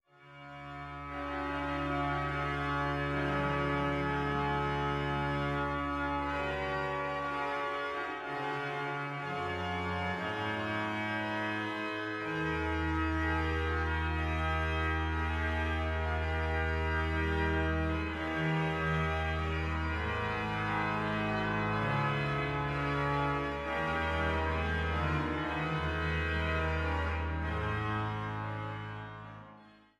Orgel in Freiberg (beide Domorgeln), Helbigsdorf und Oederan